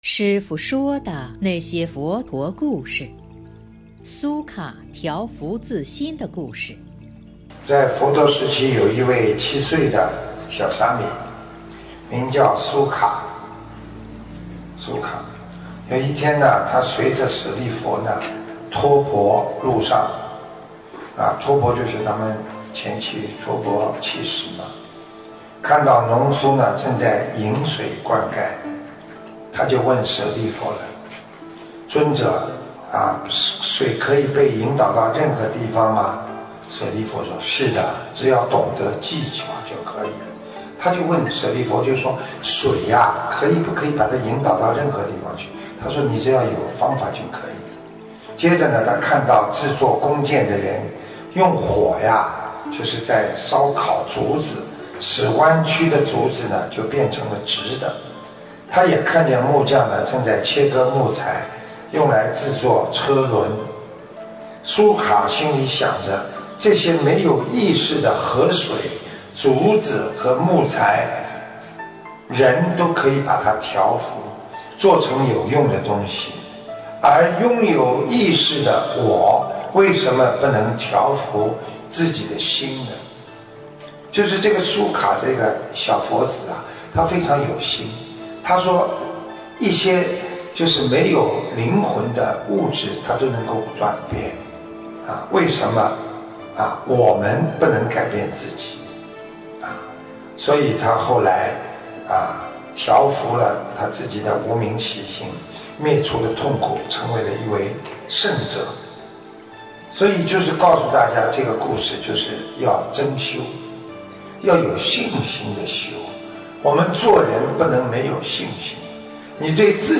（后附师父解说）2020年02月12日【师父原声音】